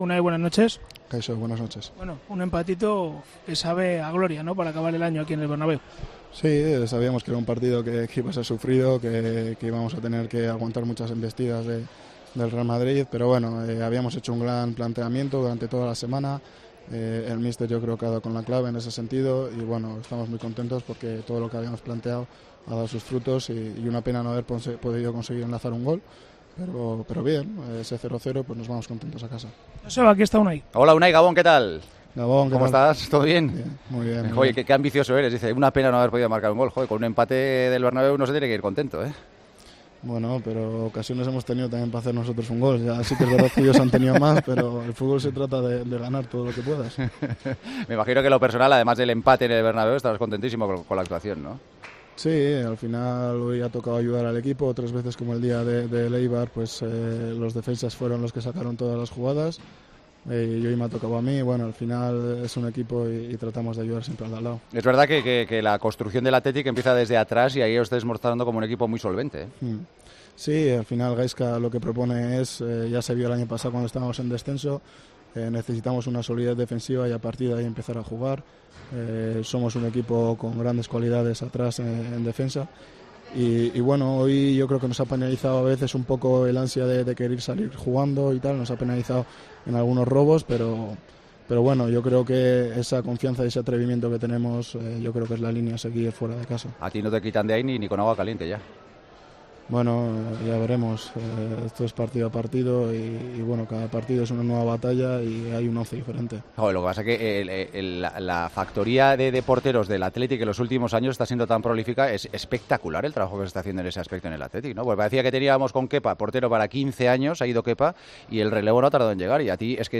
Unai atendió a Tiempo de Juego, donde definió el partido como "sufrido, sabíamos que iba a serlo, que nos tocaría aguantar embestidas del Real Madrid pero hemos hecho un gran planteamiento.